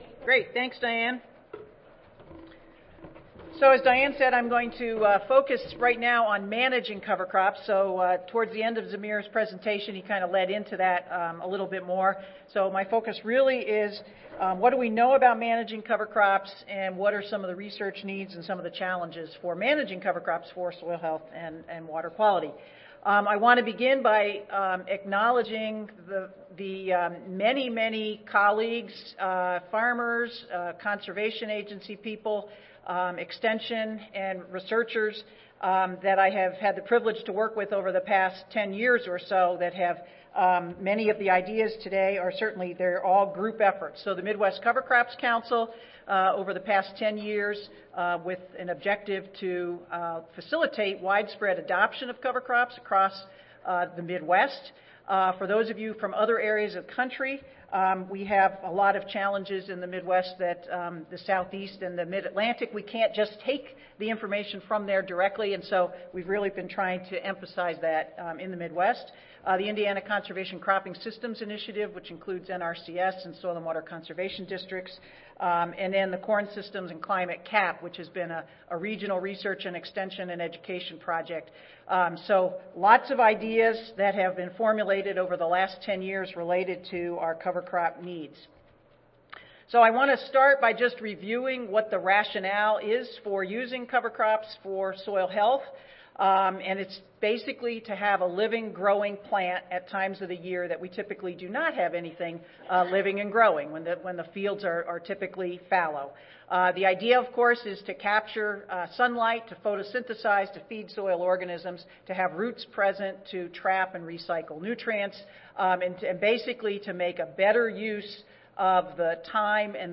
See more from this Division: ASA Section: Environmental Quality See more from this Session: Symposium--Field Management for Improved Soil Health and Environmental Quality